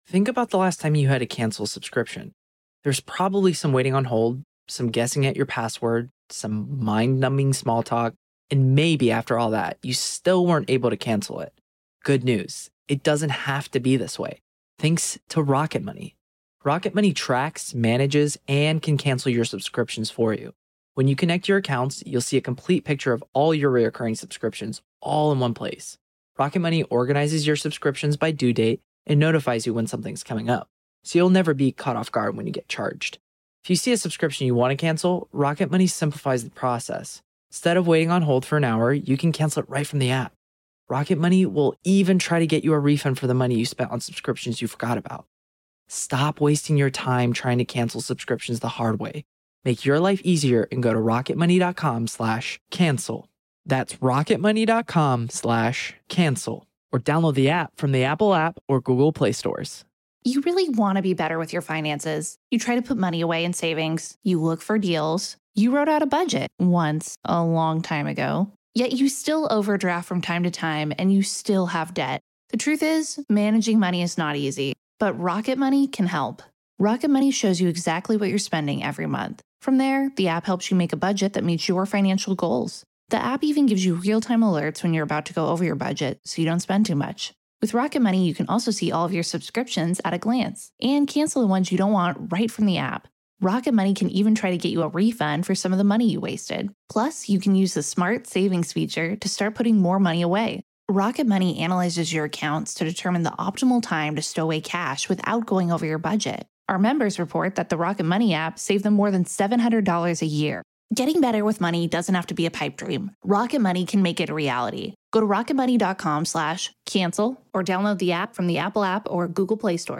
This isn’t about gym memberships or complicated routines—it’s about discovering how walking can transform your body, lift your mood, and reconnect you with yourself. Each episode, I’ll share inspiring stories, and conversations with experts to help you turn everyday walk